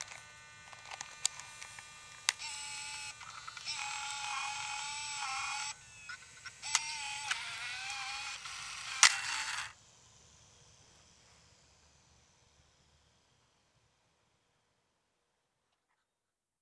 MiniDV_Tape_Eject_1.wav